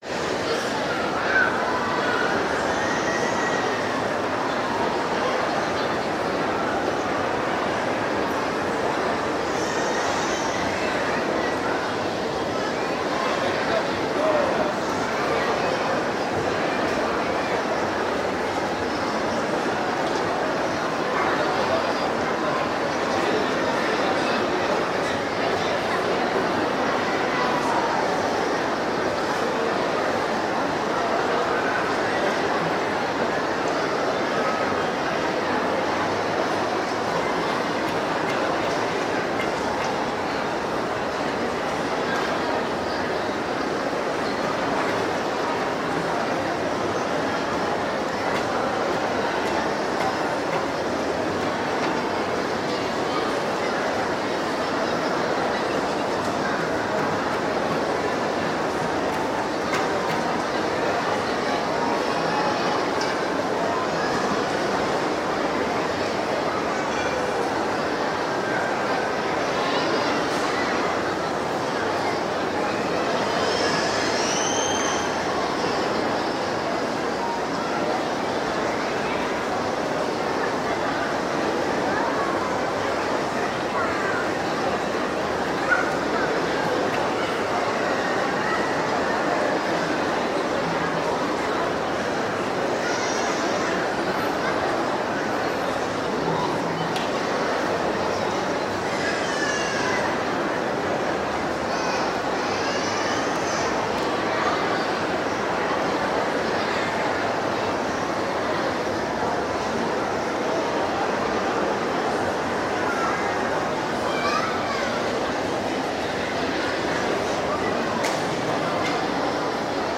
描述：一个火车站的领域记录在布宜诺斯艾利斯阿根廷。
Tag: 现场录音 街道 火车站 城市